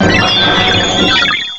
cry_not_arceus.aif